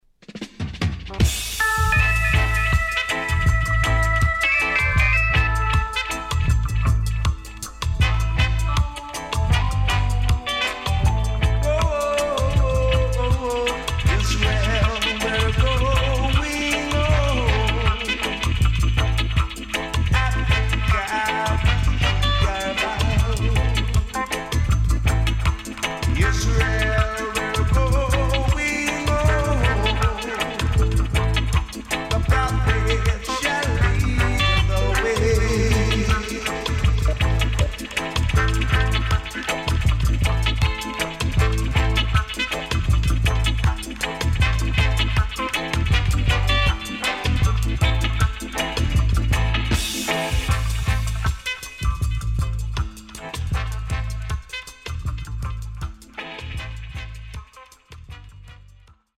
HOME > REISSUE [REGGAE / ROOTS]